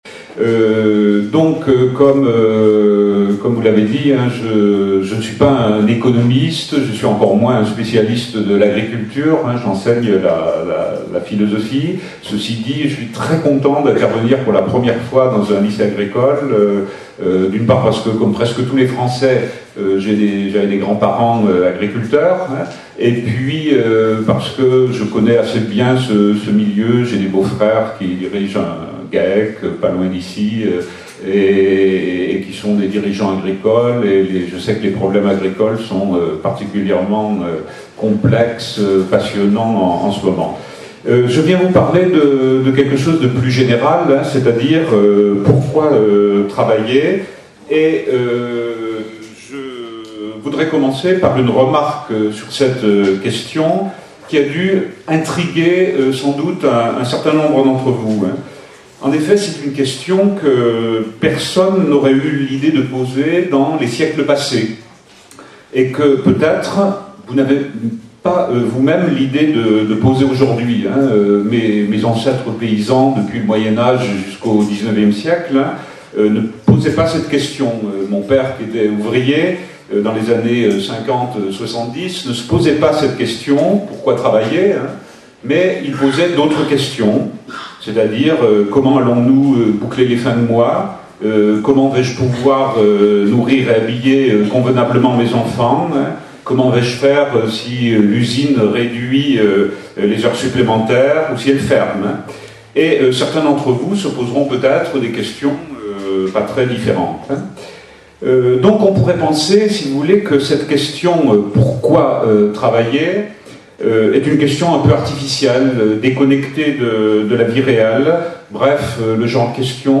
Une conférence de l'UTLS au Lycée Pourquoi travailler